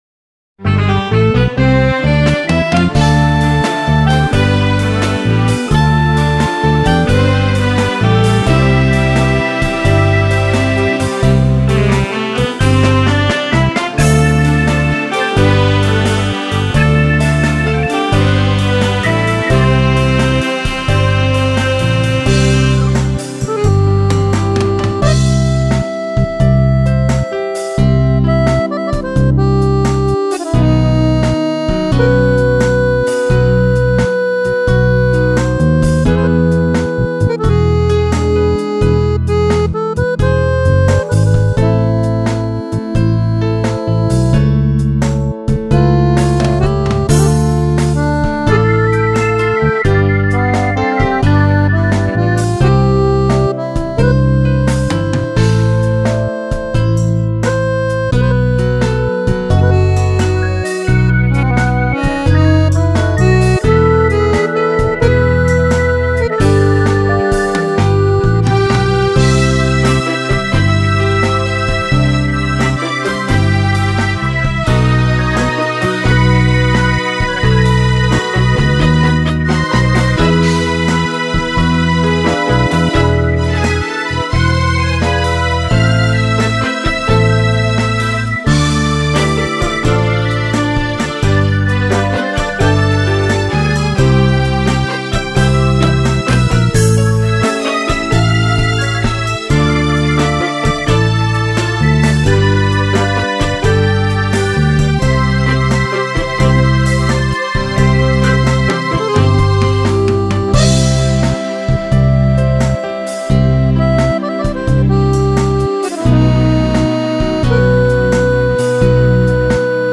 2981   03:51:00   Faixa: 8    Clássica